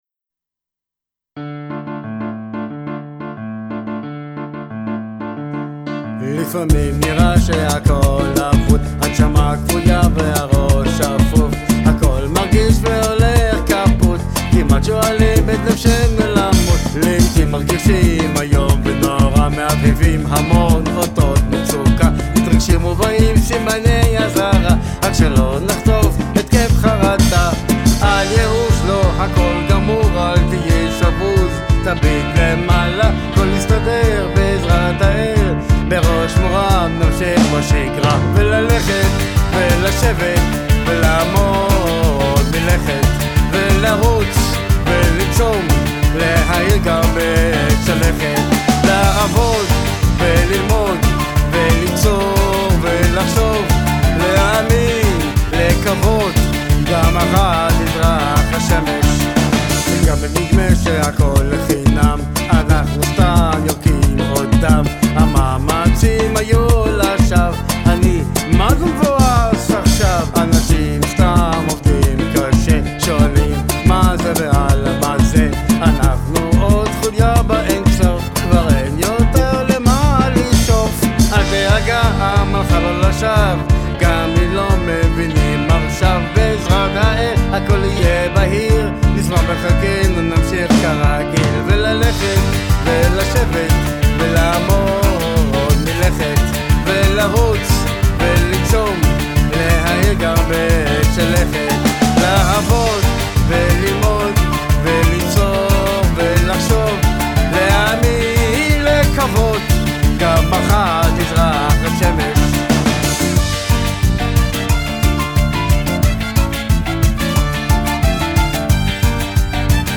צריך רק הקלטה ופלייבק יותר אייכותיים-מקצועיים ואתה תהיה ספצ’…
לדעתי צריך קצת “סייד צאן” בין הפלייבק לווקאל, לפעמים לא שומעים את הזמר מרוב הווליום של המקצב והכלים,
התופים בווליום יחסית גבוה מה שבולע את הזמר יש ברקע גיטרות ופסנתר וחצוצרות מאד יפים שנבלעים גם הם תקמפרס את כולם לסיום זה קצת יותר מורכב ליישר את הזמר לקצב…
מזכיר קצת סגנון שירי הפרטיזנים